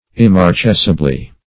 immarcescibly - definition of immarcescibly - synonyms, pronunciation, spelling from Free Dictionary Search Result for " immarcescibly" : The Collaborative International Dictionary of English v.0.48: Immarcescibly \Im`mar*ces"ci*bly\, adv.
immarcescibly.mp3